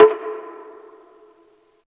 176TTPERC1-L.wav